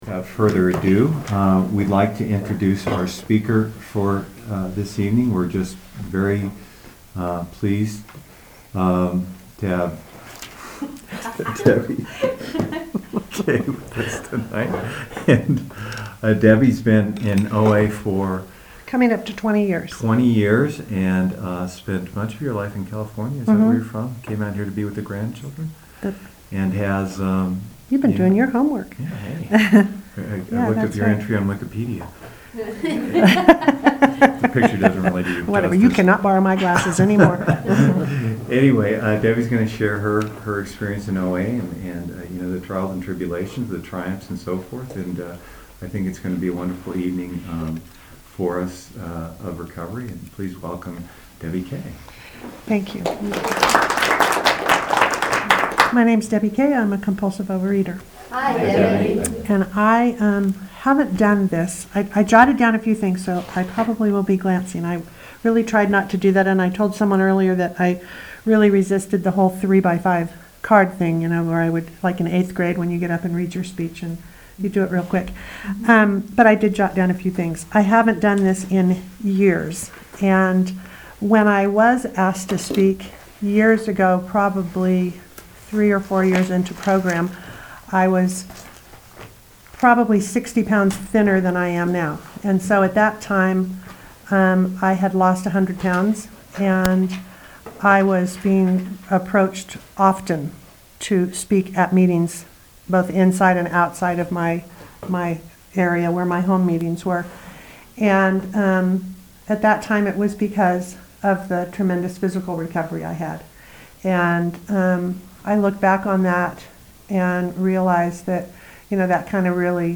Speakers Meeting